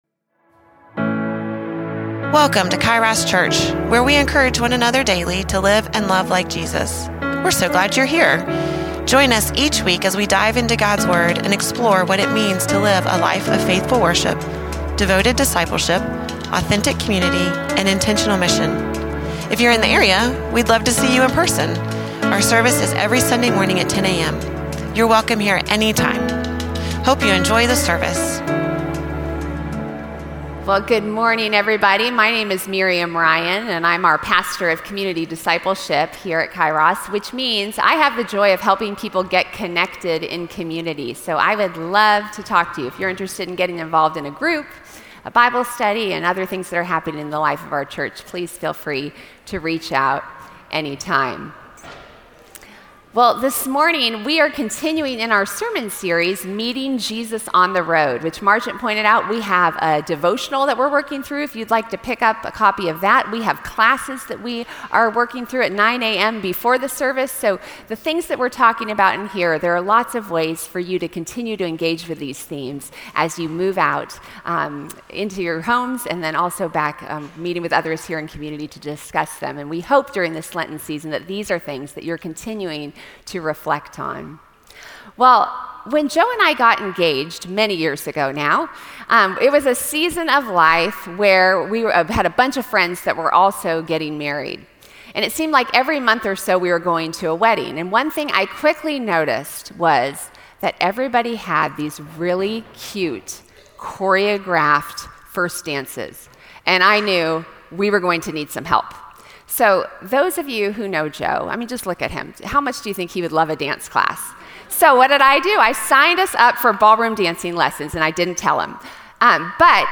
Sermons – Kairos Church
Service-3.1.26-SERMON-1.mp3